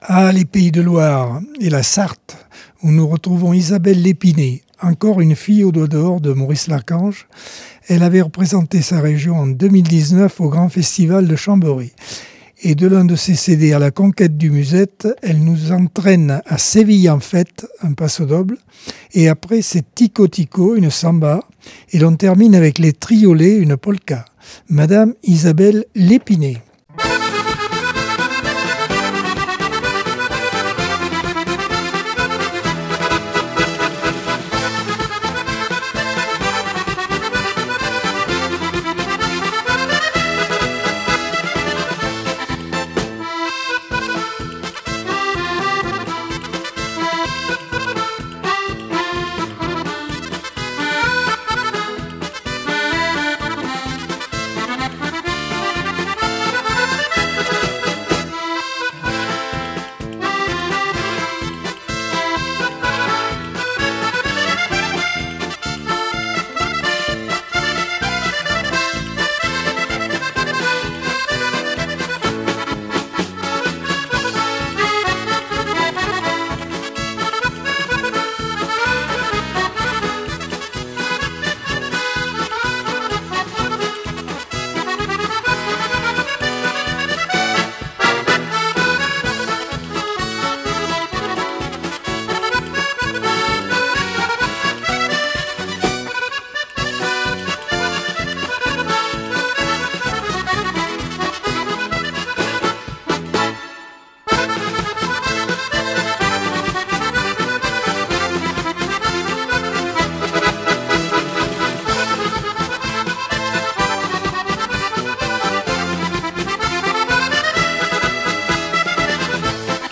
Accordeon 2025 sem 07 bloc 4 - Radio ACX